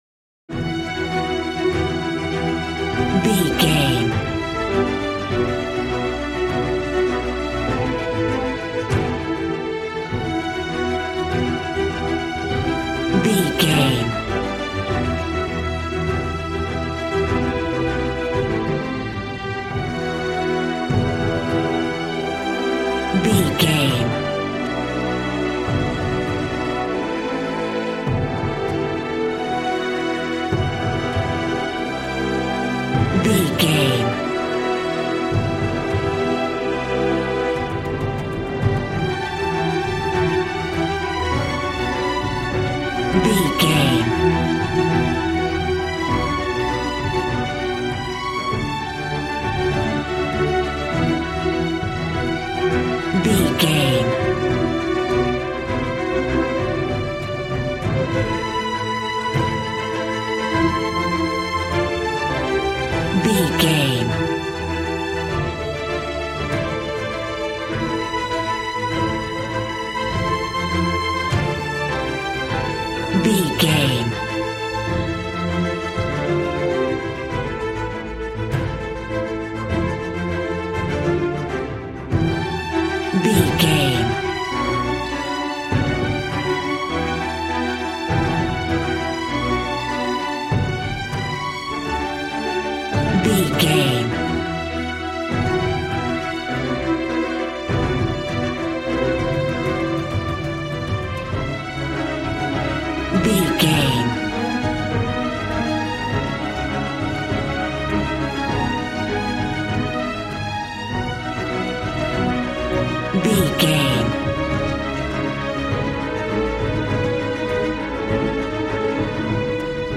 Aeolian/Minor
E♭
suspense
piano
synthesiser